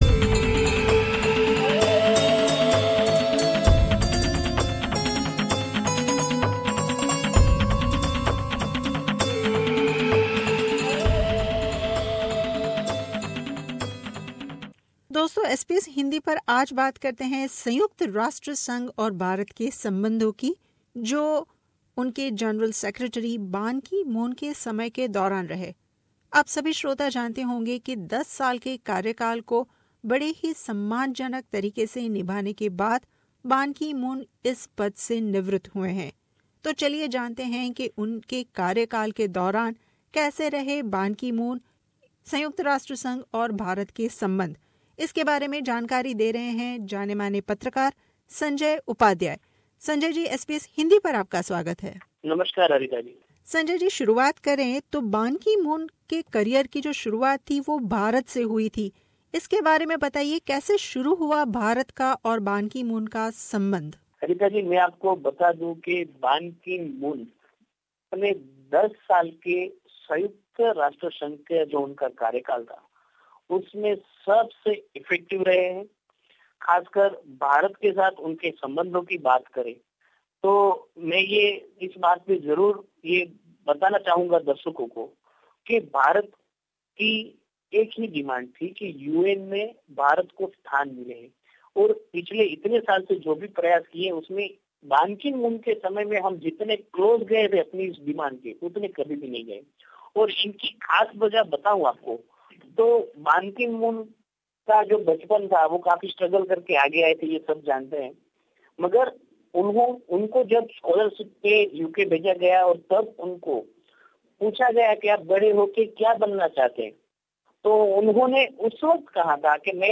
बातचीत में जानते है बान की मून और भारत के सम्बन्ध के बारे में